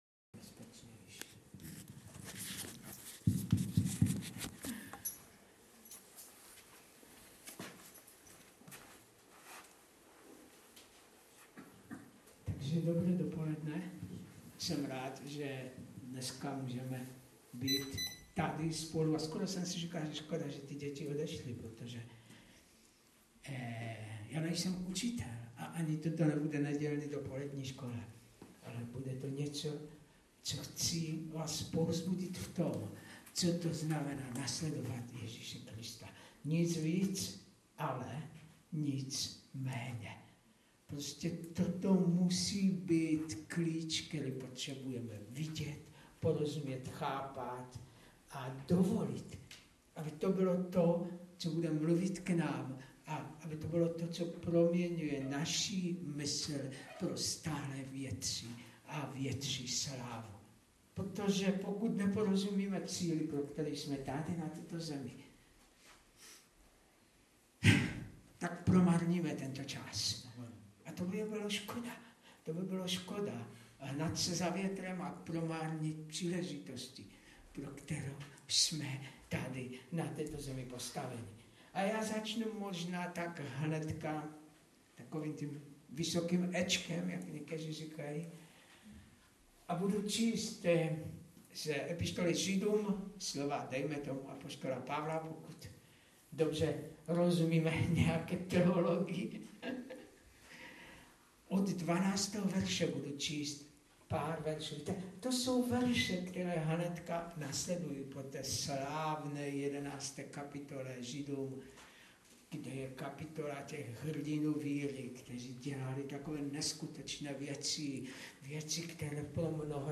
Omlouváme se za sníženou kvalitu a nižší hlasitost časem možná bude i lepší kvalita.
Kázání